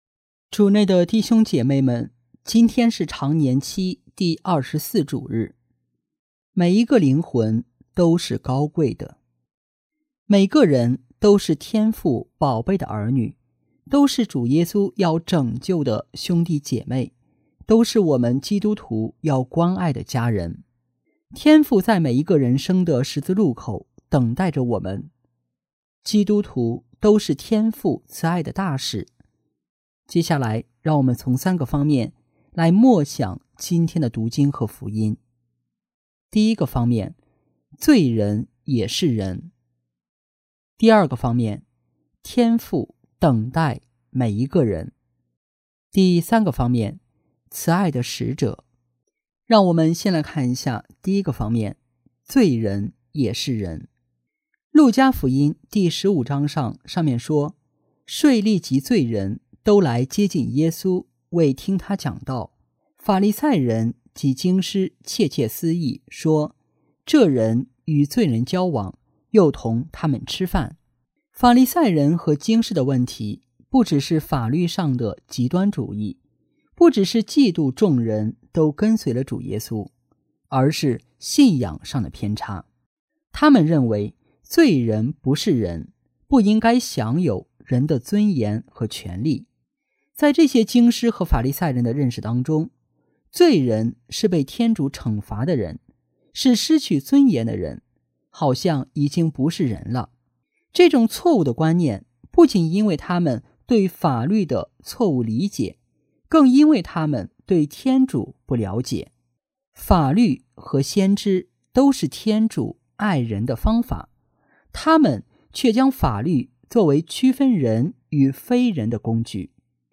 【主日证道】| 每一个灵魂都是高贵的（丙-常年期第24主日）